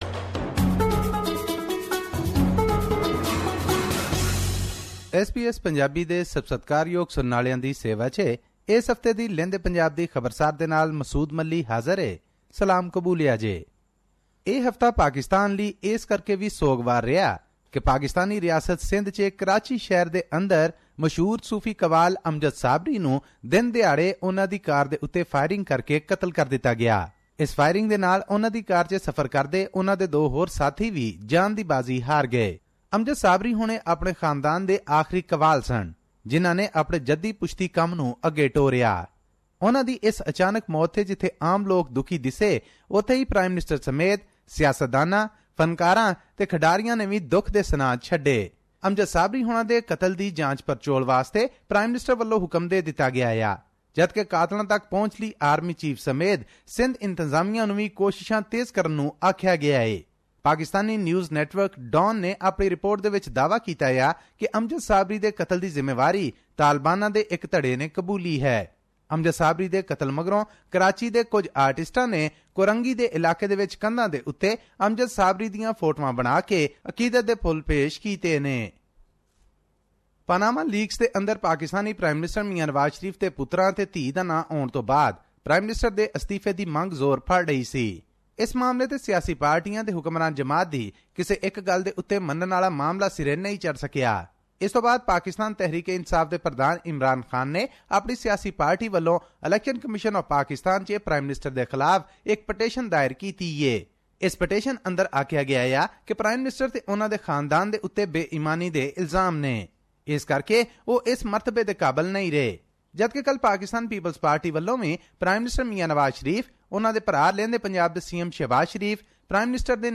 weekly news report